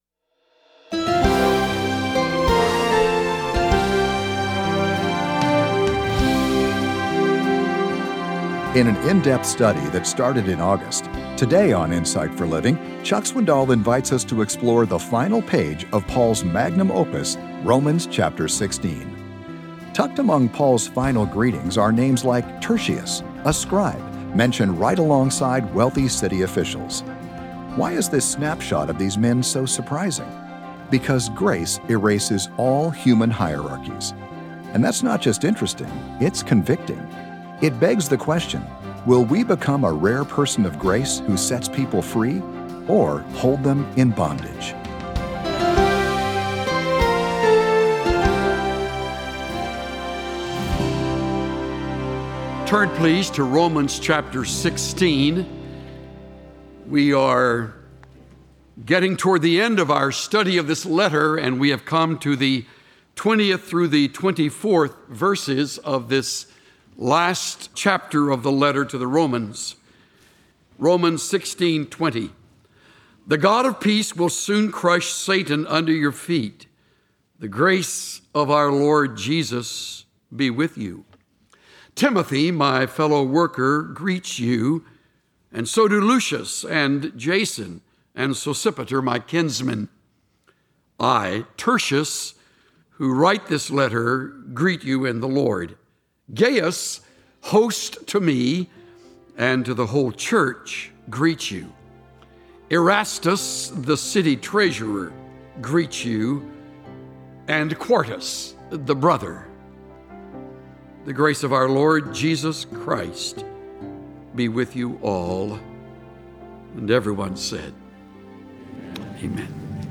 Join Pastor Chuck Swindoll as he explores some of Paul's final words to the Roman church (Romans 16). As you acknowledge the presence of spiritual warfare, you can also stand firm with a shield of faith and with a heart full of grace.